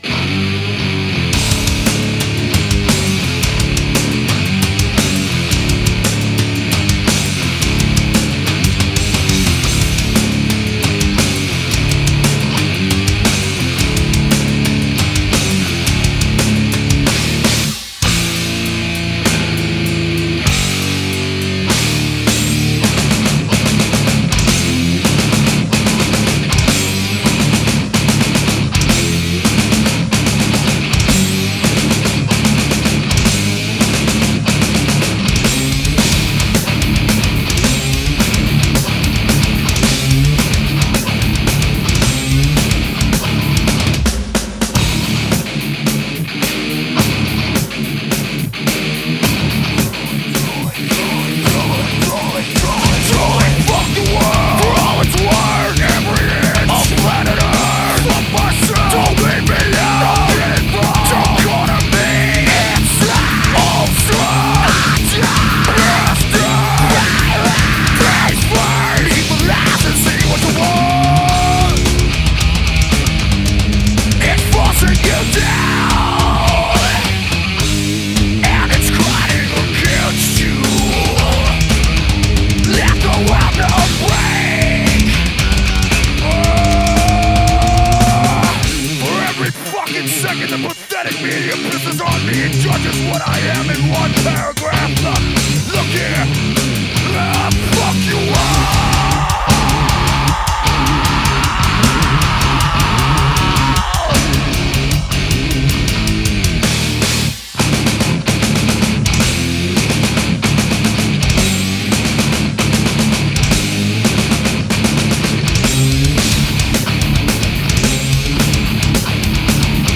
Groove metal